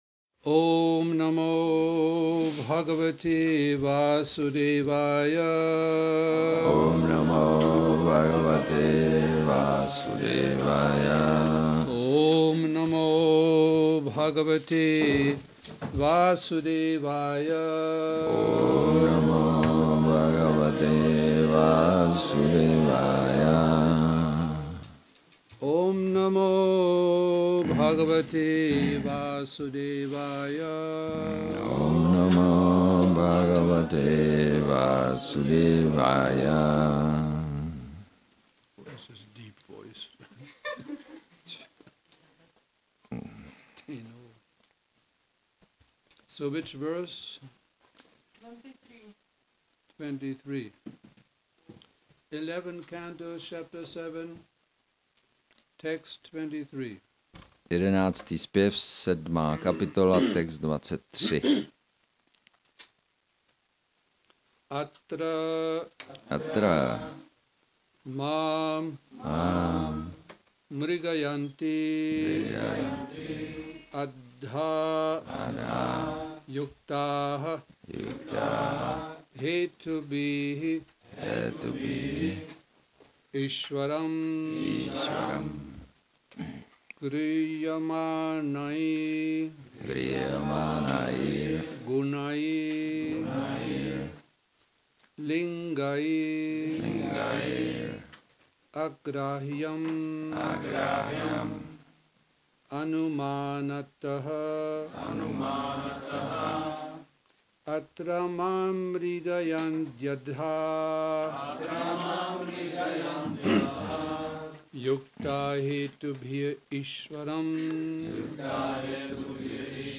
Šrí Šrí Nitái Navadvípačandra mandir
Přednáška SB-11.7.23